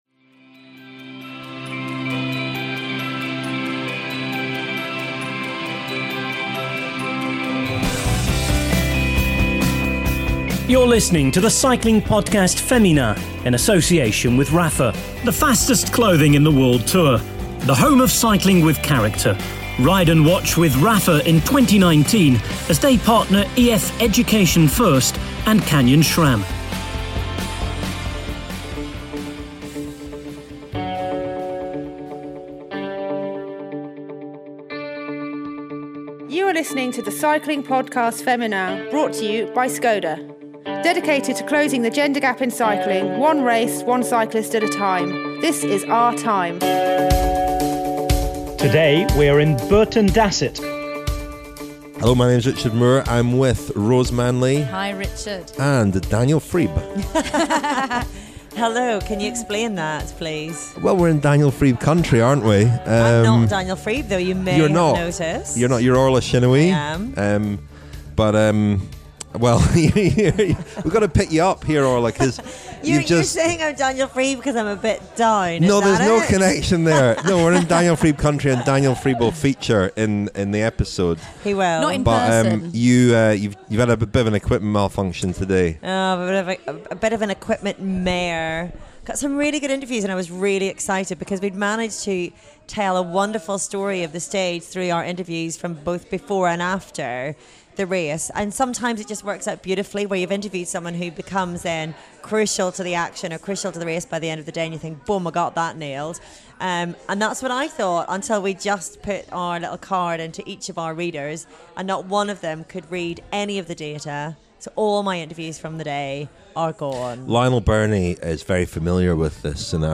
There are interviews with both riders in The Cycling Podcast Féminin as Orla Chennaoui